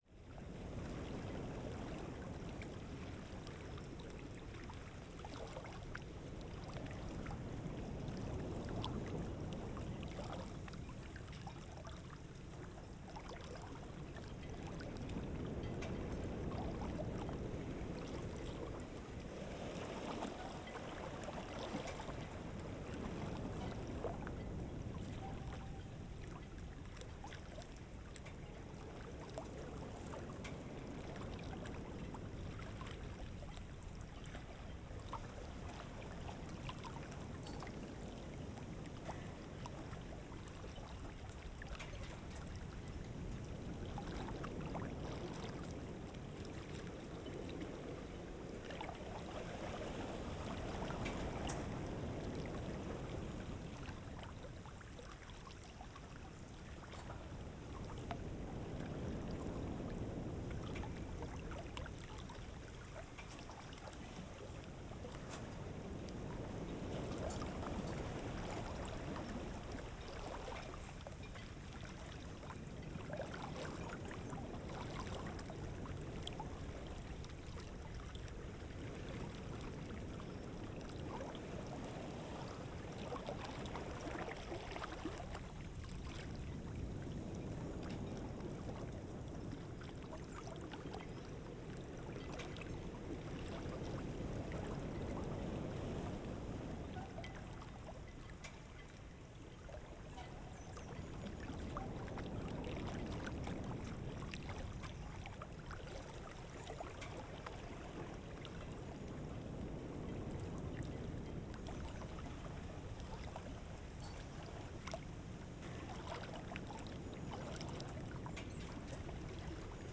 03 - Vagues pendant le lever du soleil.flac